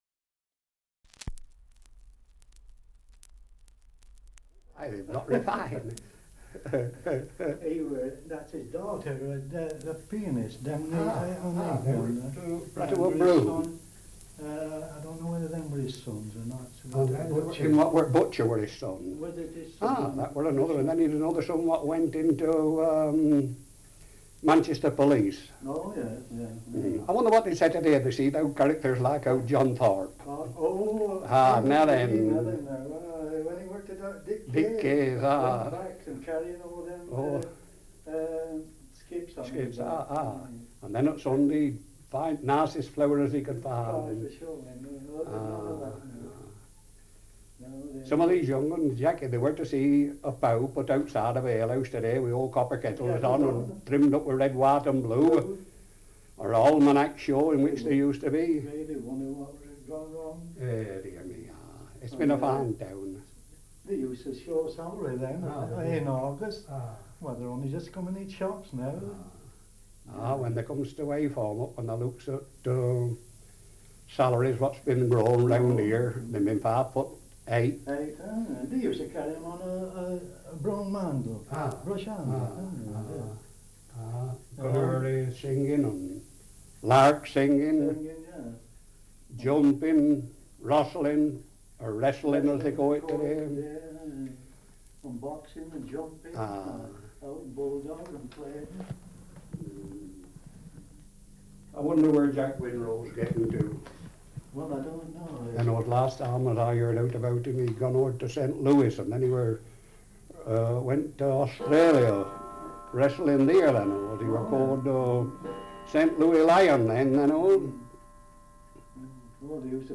Dialect recording in Heywood, Lancashire
78 r.p.m., cellulose nitrate on aluminium